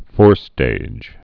(fôrstāj)